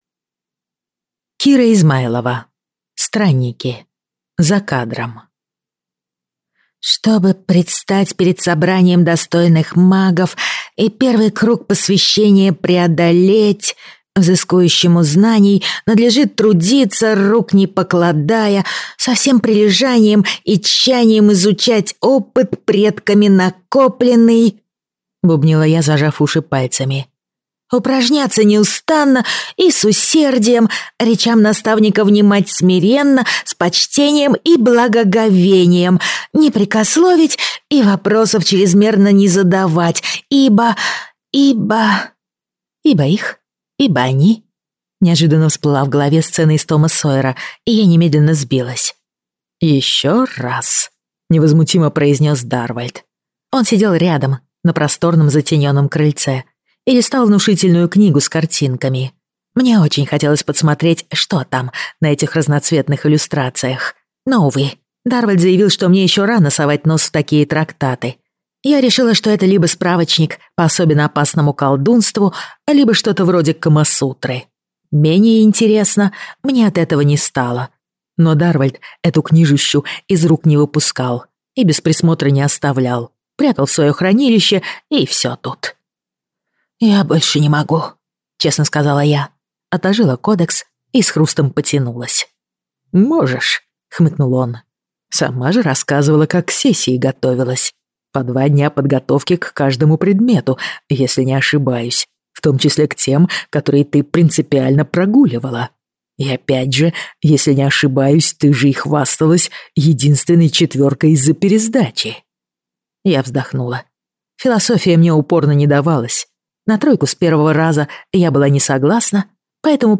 Aудиокнига Странники.